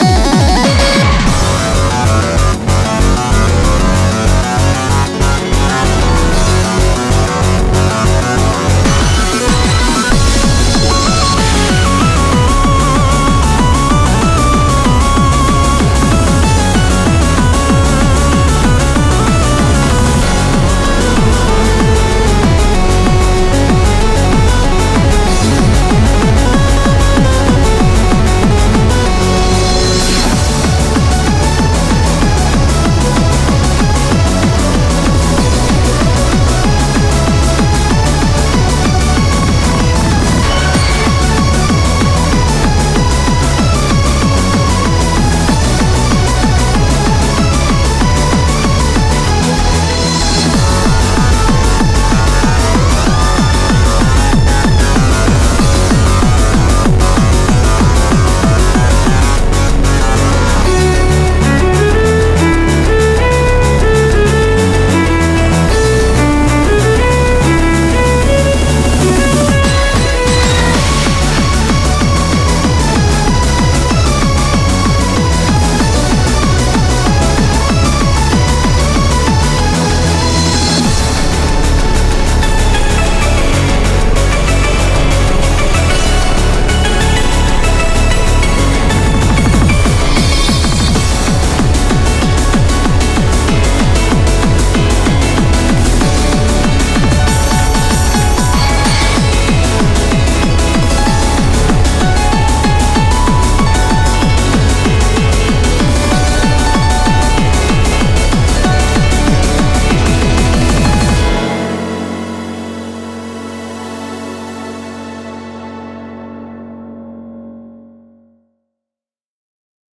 BPM190
Typical trance core